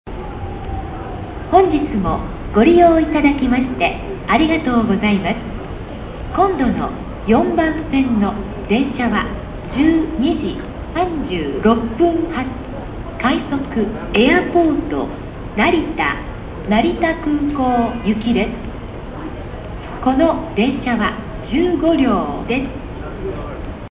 次発放送エアポート成田次発放送です。
「エアポート」と「成田」がツギハギになっています。これは「成田行き」の次発放送用の単語と共有しているからです。
next-airportnarita.mp3